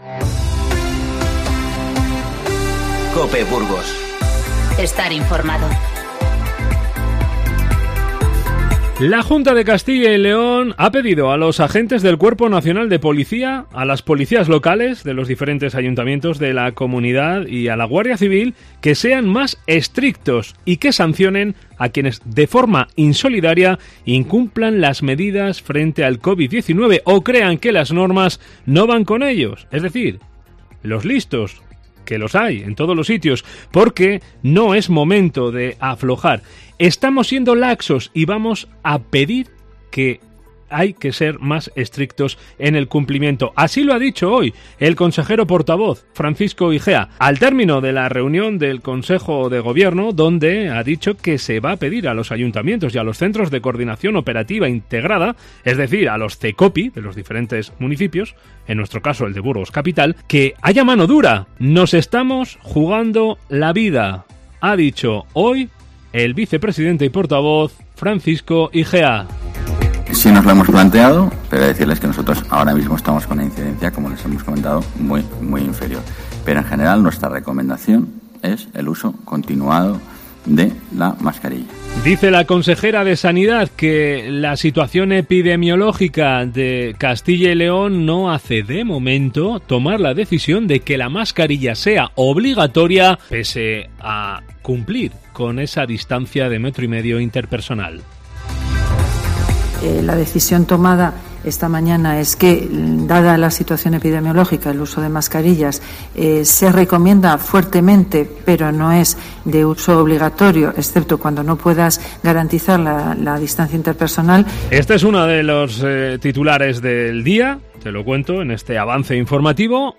INFORMATIVO Mediodía